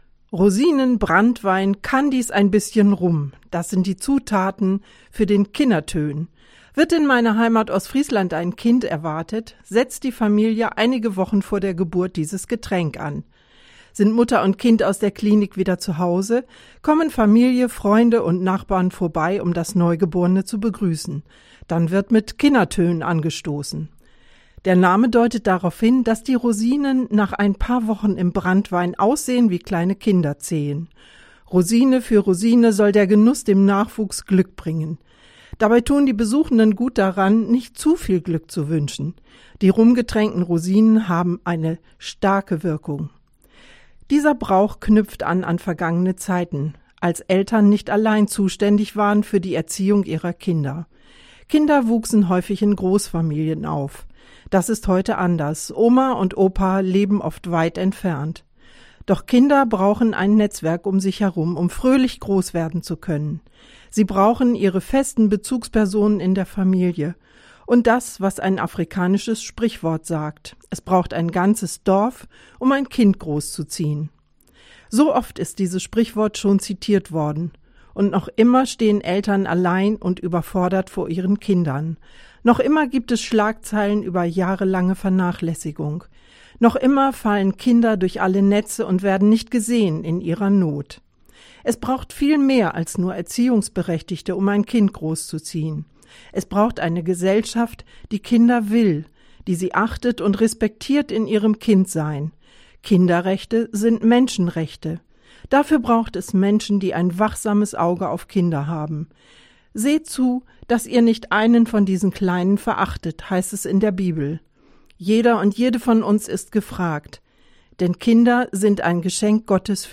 Radioandacht vom 13. Juli